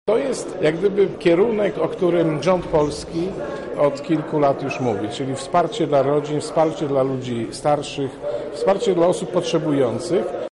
– twierdzi Piotr Gawryszczak, radny PiS.